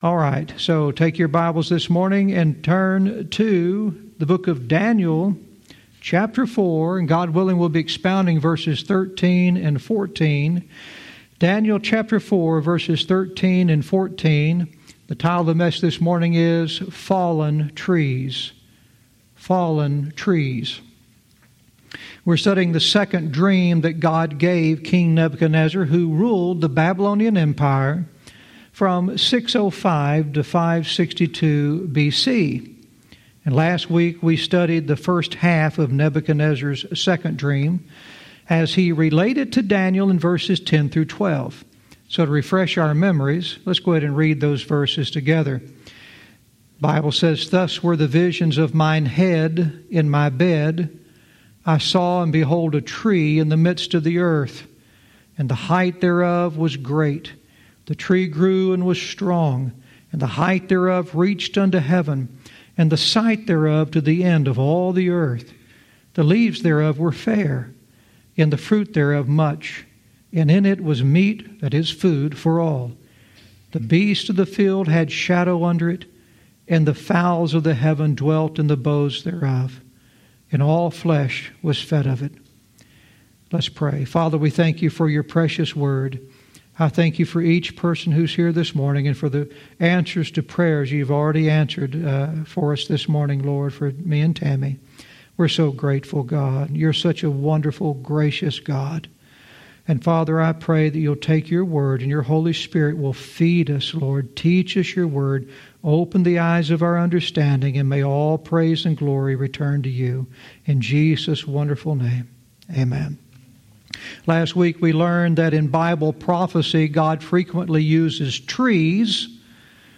Verse by verse teaching - Daniel 4:13-14 "Fallen Trees"